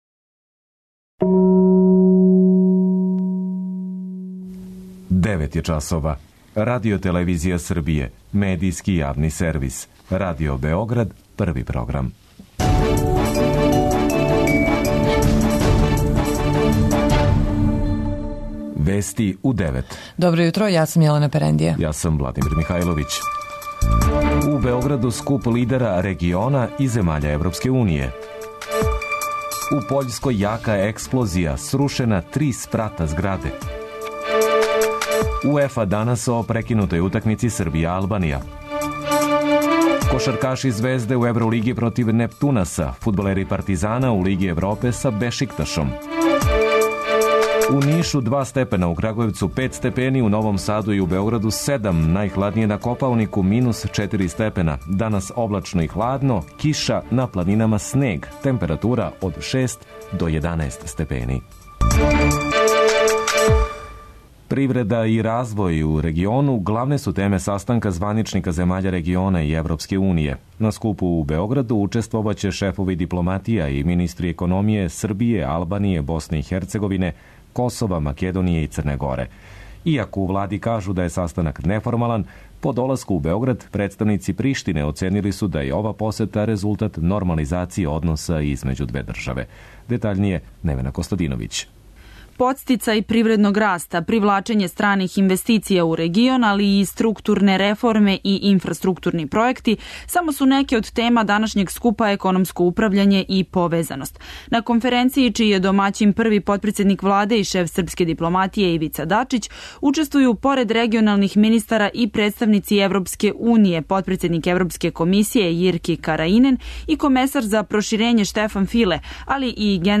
преузми : 10.34 MB Вести у 9 Autor: разни аутори Преглед најважнијиx информација из земље из света.